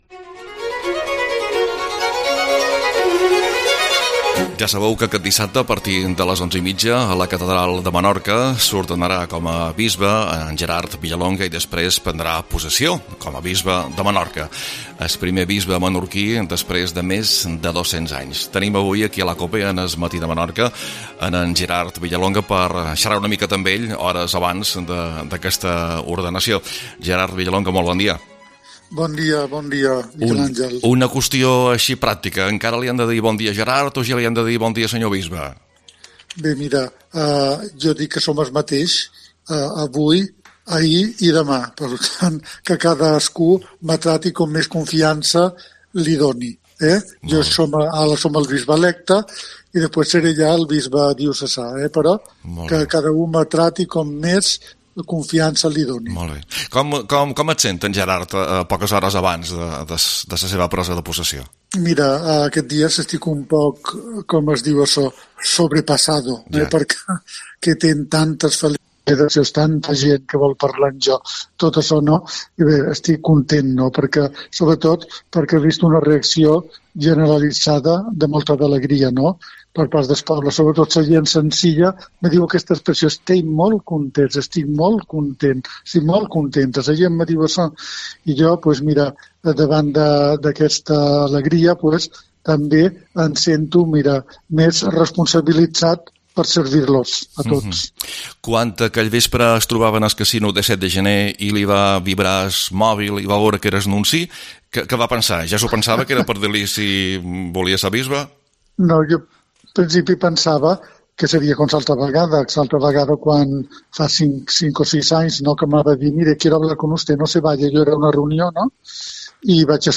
AUDIO: Entrevista amb el Bisbe electe de Menorca, Gerard Villalonga, poques hores abans de la seva ordenacio